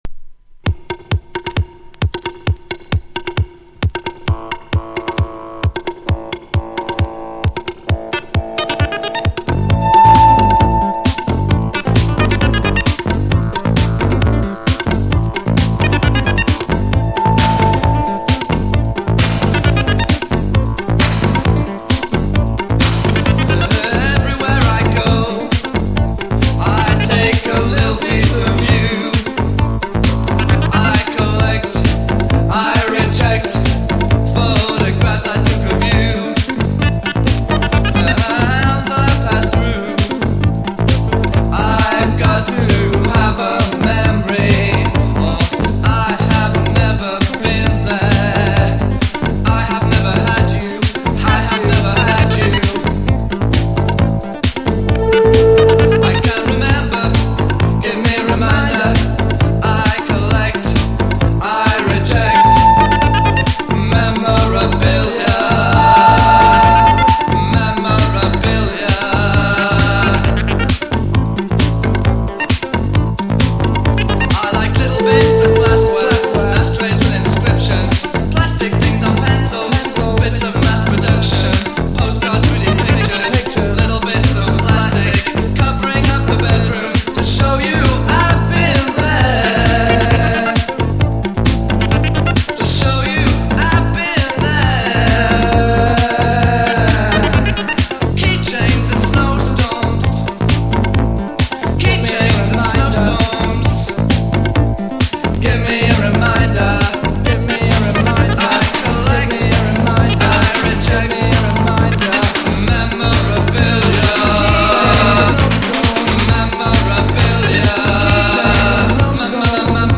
GENRE Dance Classic
BPM 146〜150BPM
GARAGE_CLASSIC
ニューウェーブ
ロック